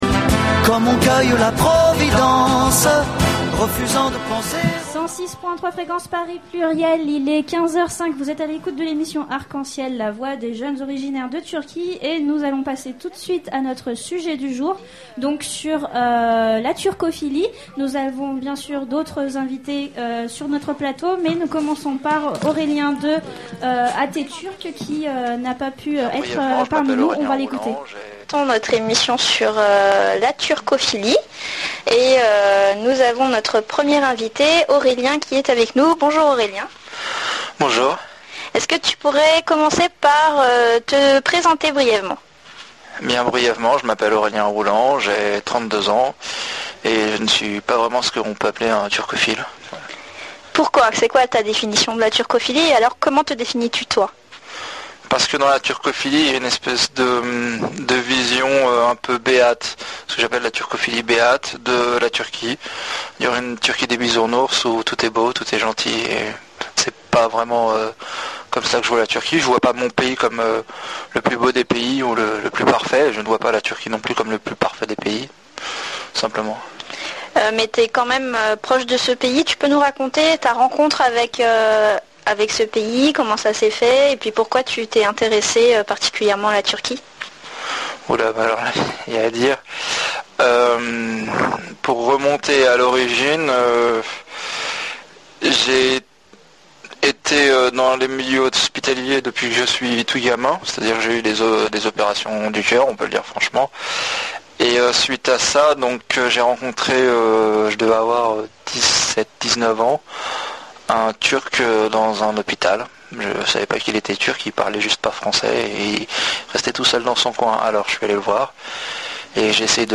Samedi 17 novembre 2007 de 15h00 à 16h00 Radio Arc En Ciel a diffusé une émission dont le sujet était « la turcophilie ».